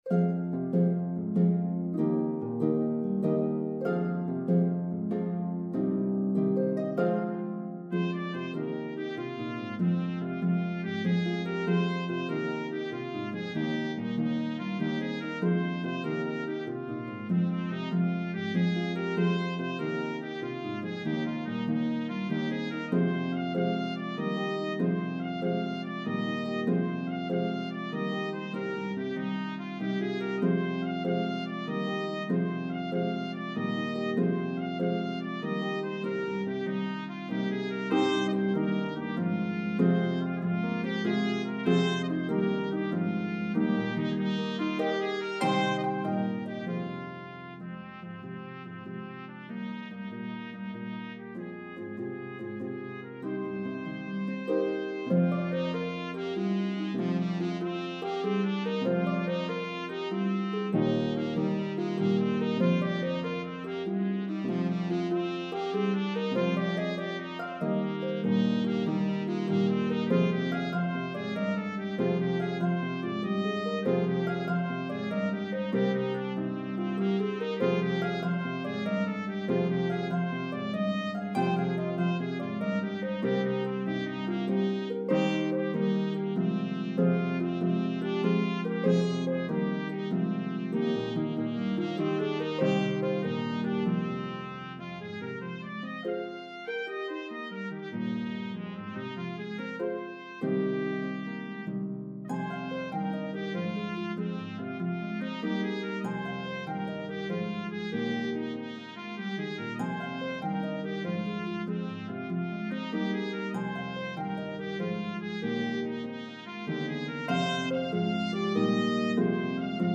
One of the most energetic Step Dances in Slip Jig form.